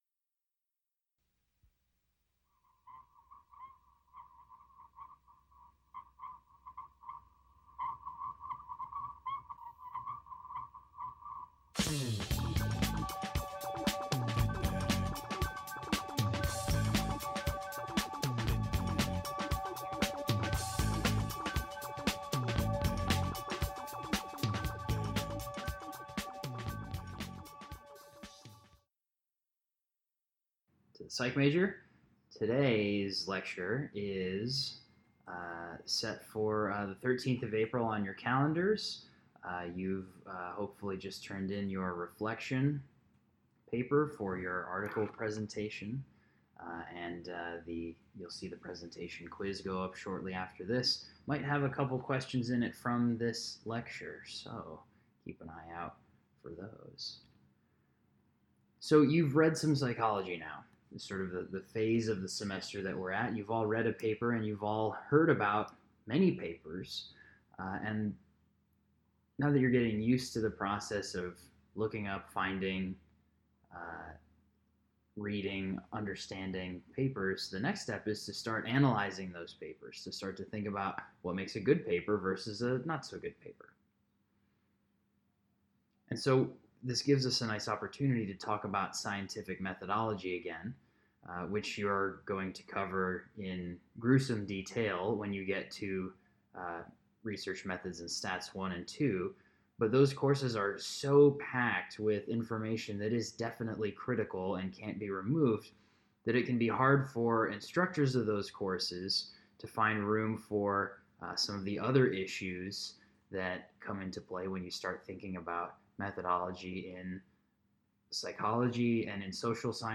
One part is the lecture I posted last week, and the second is this discussion of Simmons, Nelson, & Simonsohn's 2011 paper in which they offer the concept of researcher degrees of freedom.